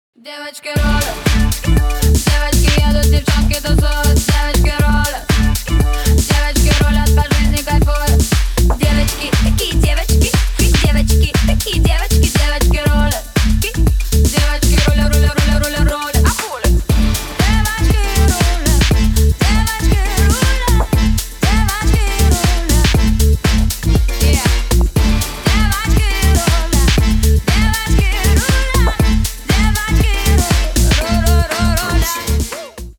Жанр: Русская поп-музыка / Русские